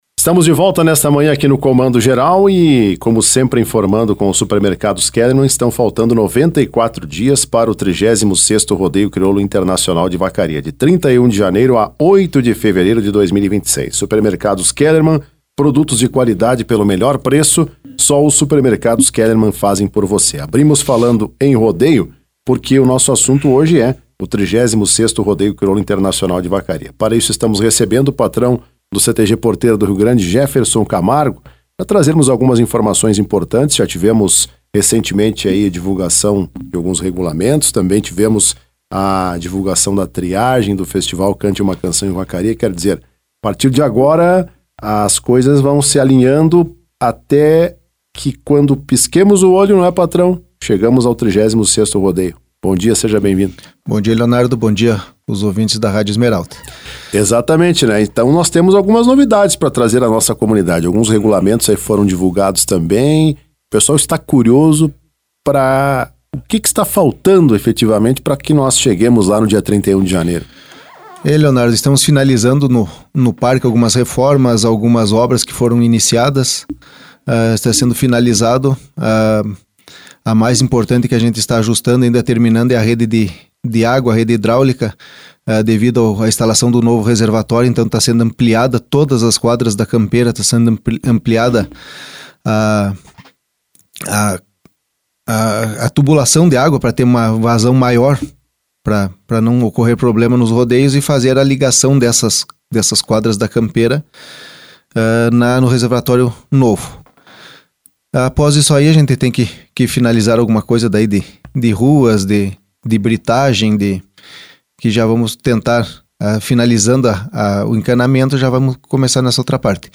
Em entrevista à rádio Esmeralda nesta quarta-feira